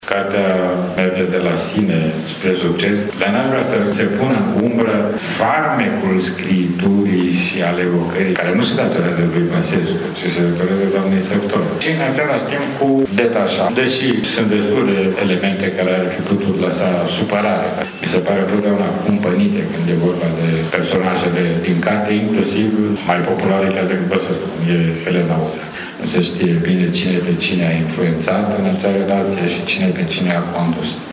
O carte incomodă pentru unele personaje politice, spune acesta, dar de ajutor românilor: